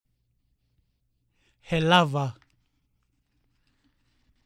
The 14 Rotuman consonants are pronounced below at the beginnings of words before each of the 5 vowels. Pay attention to the /g/, which sounds like English ng, /j/, which sounds like English ch, and the glottal stop /‘/, which sounds like the catch in the throat in the middle of oh-oh.